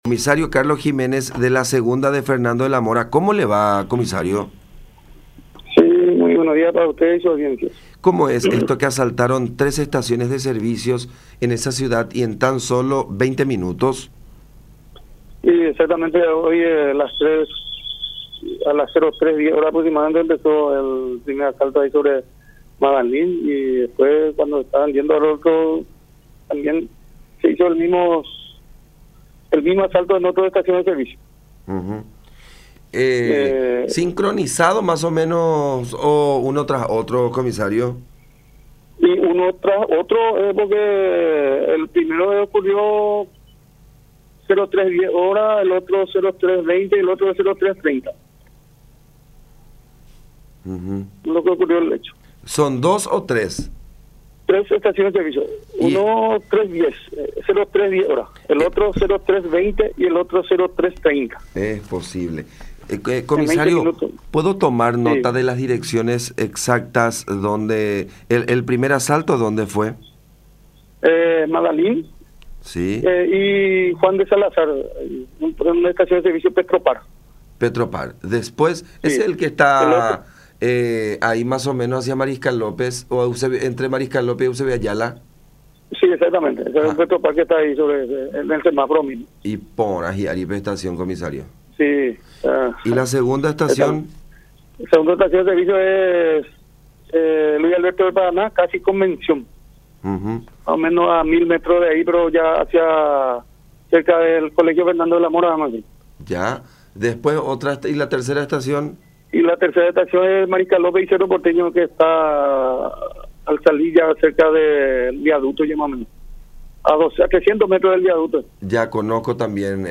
“Uno de los playeros fue golpeado en la cabeza de un culatazo, pero afortunadamente fue el único herido en los tres asaltos”, expresó en comunicación con La Unión.